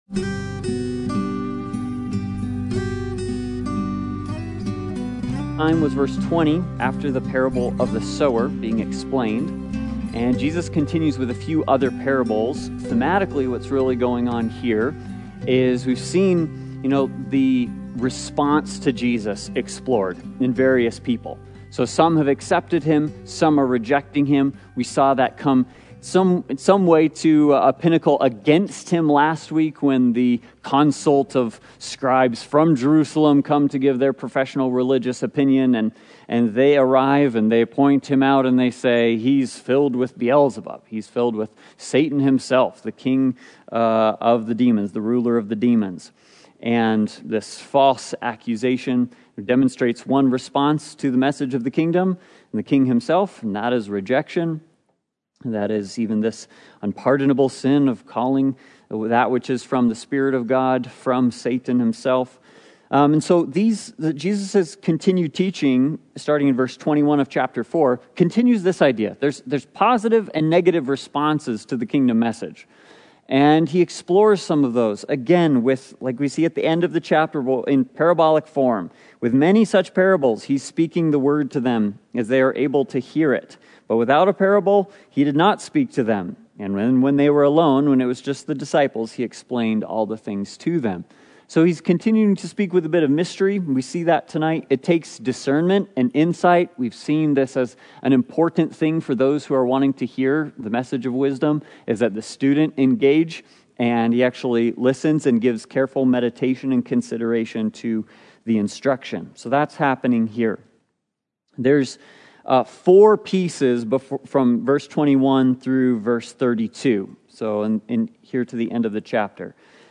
Service Type: Sunday Bible Study